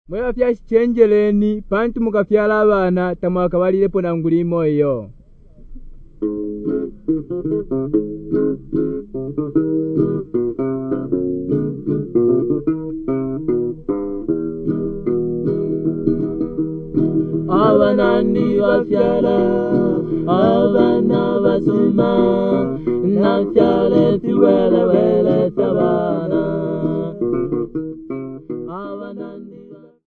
Folk music--Africa
Field recordings
Africa Zambia Mulfulira f-za
sound recording-musical